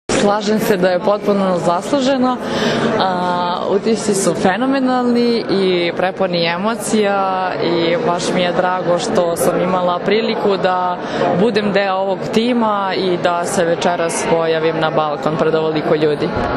Više od 10000 navijača dočekalo je večeras žensku seniorsku reprezentaciju Srbije, dvostrukog uzastopnog prvaka sveta, ispred Skupštine Beograda.
Izjava Sare Lozo